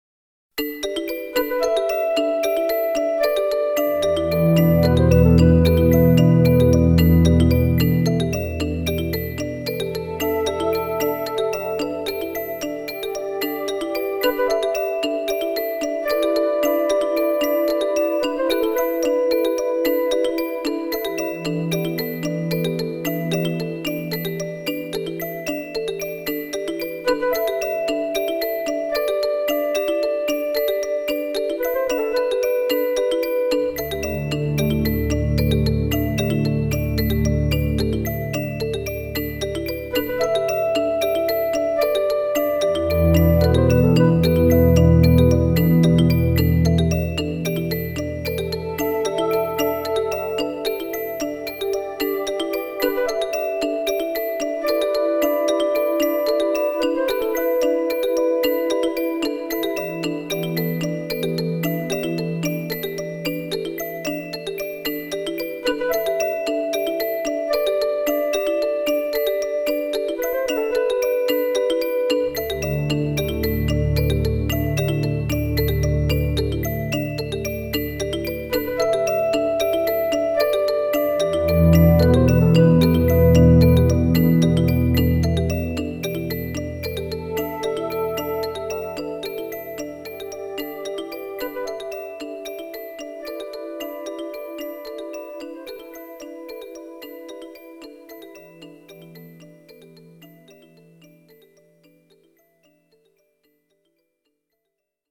as recorded from the original Roland MT-32 score!